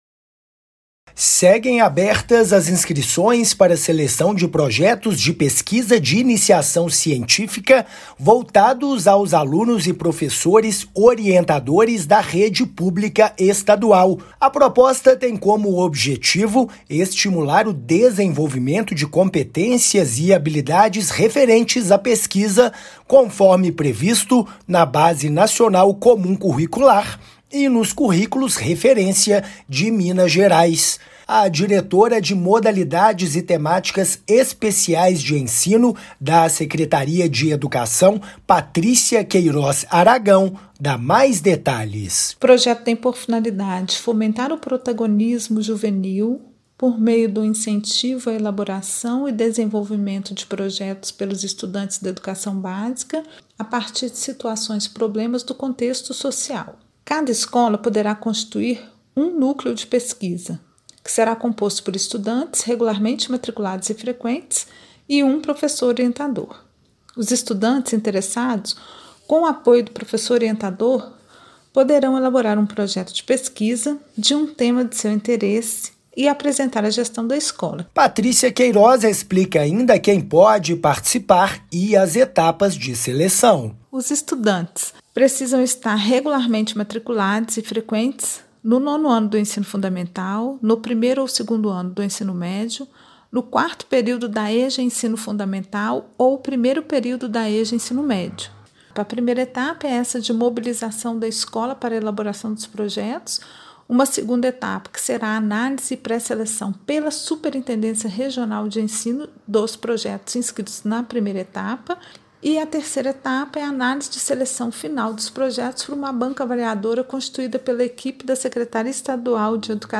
MATÉRIA_RÁDIO_INICIAÇÃO_CIENTÍFICA.mp3